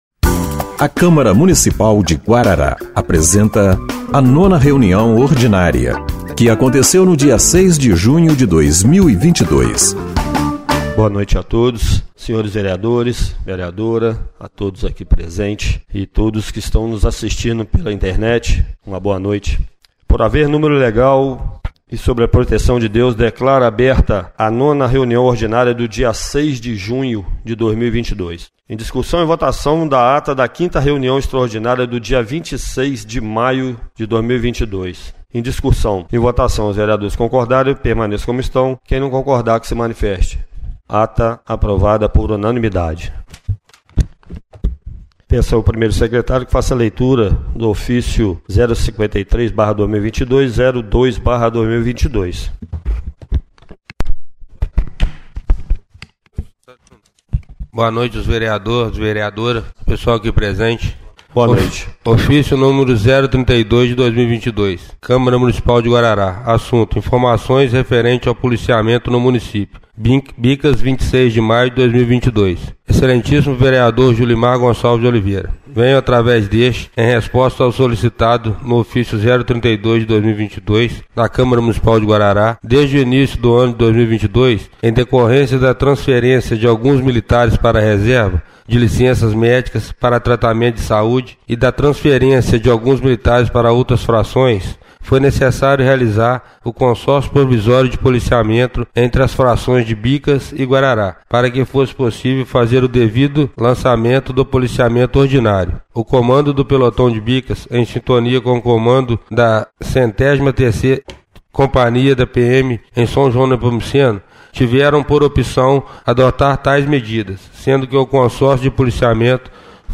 9ª Reunião Ordinária de 06/06/2022